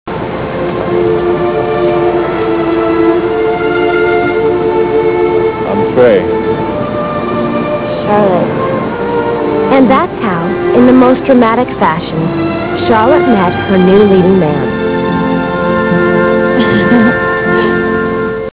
Classical/Instrumental
Comment: symphony